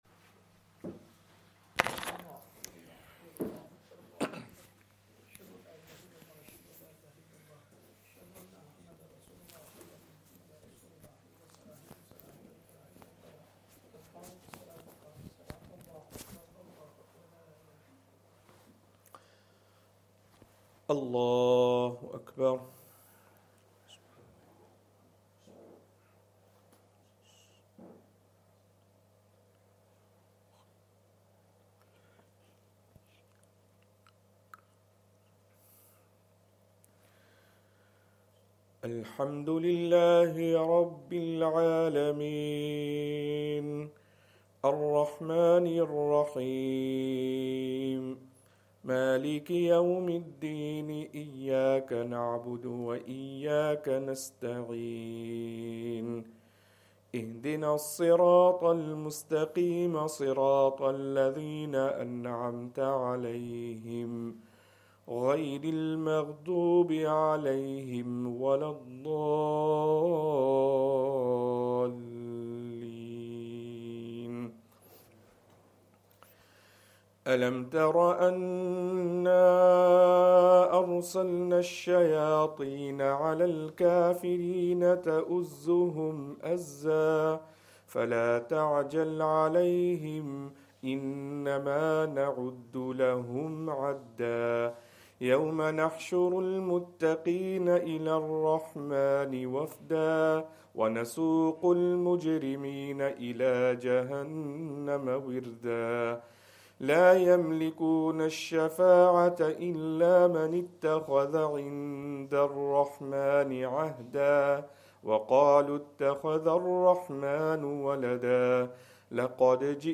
Fajr
Madni Masjid, Langside Road, Glasgow